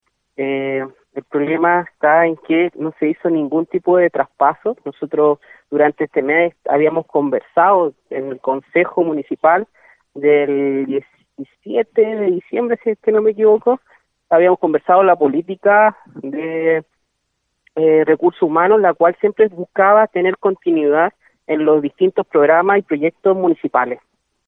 El concejal José Manuel Cartagena hizo énfasis en el impacto que está causando esta situación, tanto para los funcionarios, como para la continuidad de programas sociales dirigidos a la comunidad.